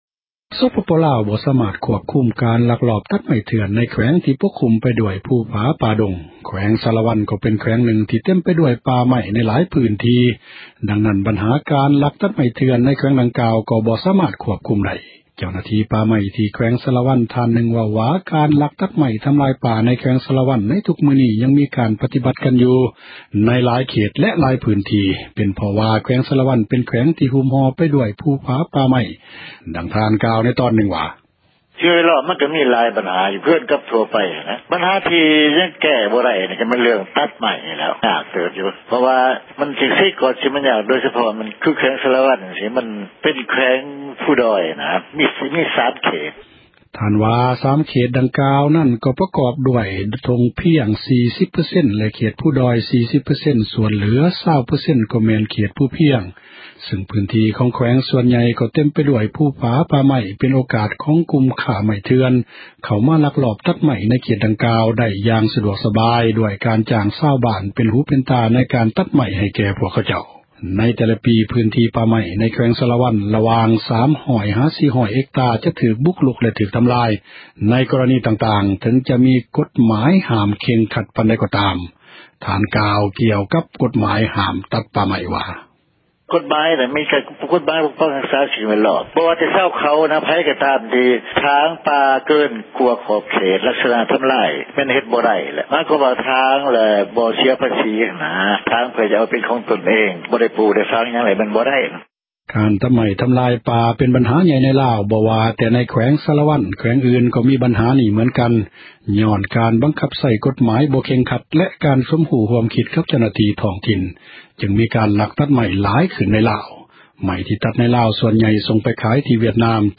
ເຈົ້າຫນ້າທີ່ ປ່າໄມ້ທີ່ ແຂວງສາຣະວັນ ທ່ານນື່ງເວົ້າວ່າ ການລັກຕັດ ໄມ້ທຳລາຍ ປ່າໃນແຂວງ ສາຣະວັນ ໃນທຸກມື້ນີ້ ຍັງມີການ ປະຕິບັດ ກັນຢູ່ໃນ ຫລາຍເຂດແລະ ຫລາຍພື້ນທີ່ ເປັນເພາະວ່າ ແຂວງ ສາຣະວັນ ເປັນແຂວງທີ່ ຫຸ້ມຫໍ່ໄປ ດ້ວຍພູຜາ ປ່າໄມ້ ດັ່ງທ່ານກ່າວ ໃນຕອນນື່ງວ່າ: